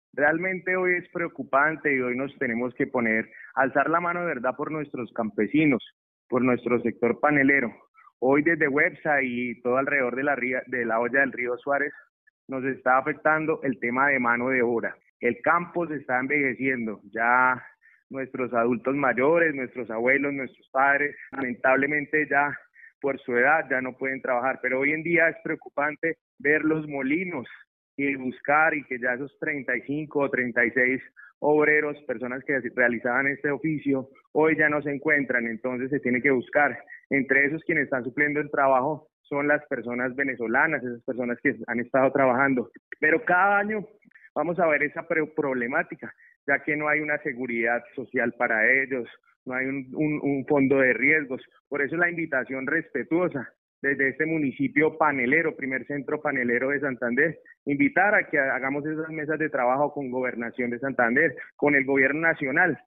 Hernando Fontecha Amado, Alcalde de Güepsa, Santander